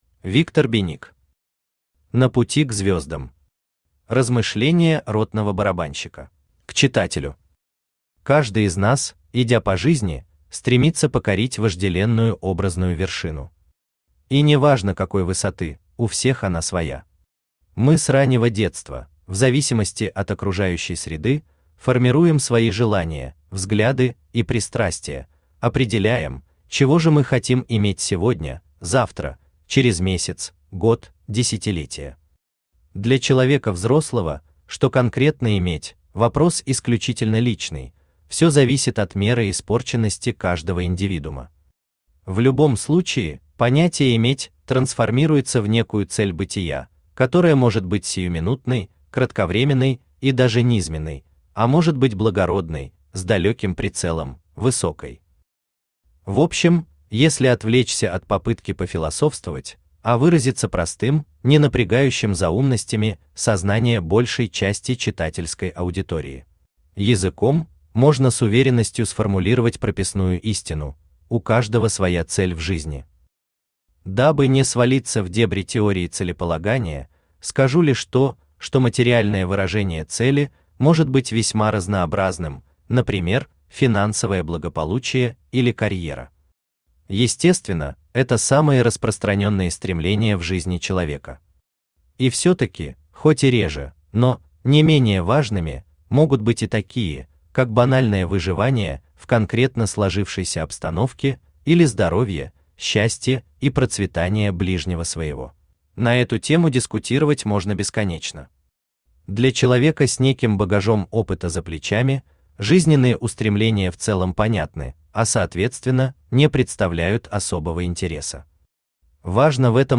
Aудиокнига На пути к звёздам. Размышления ротного барабанщика Автор Виктор Владимирович Беник Читает аудиокнигу Авточтец ЛитРес.